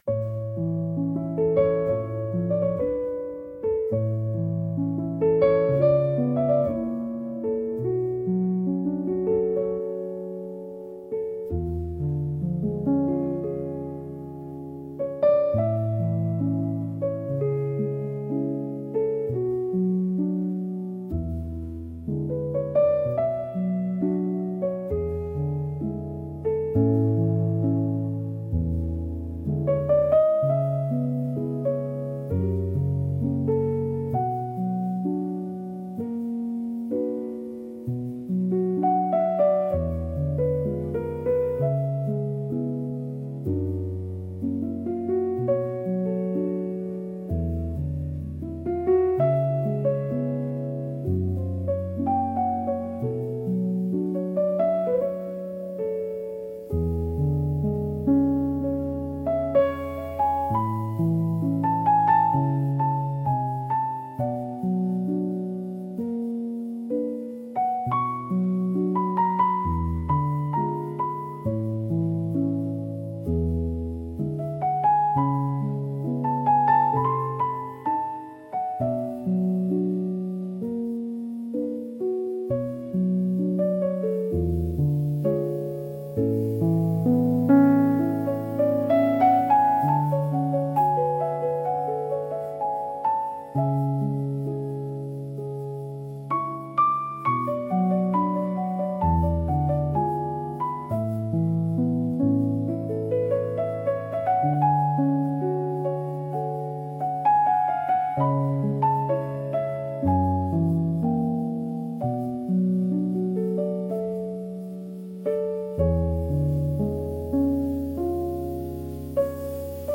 繊細で情感豊かな空気を醸し出すジャンルです。